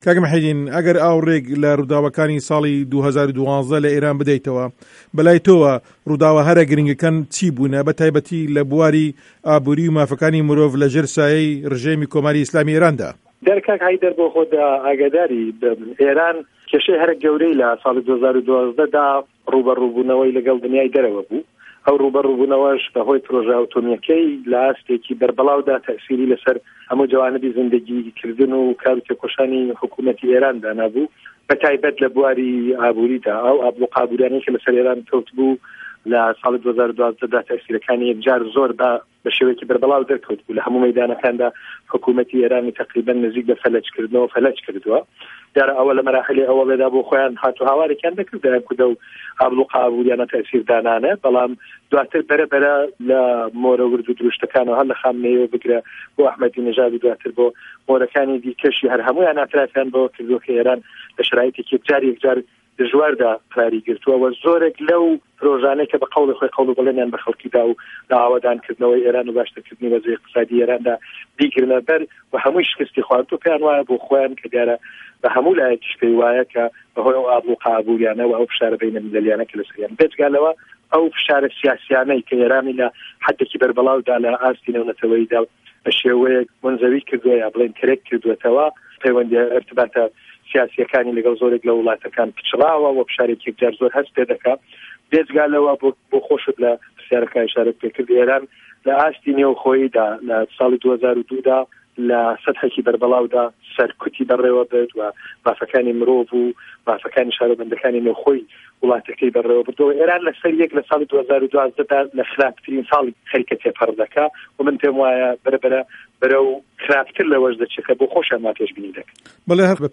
له‌ وتووێژێکدا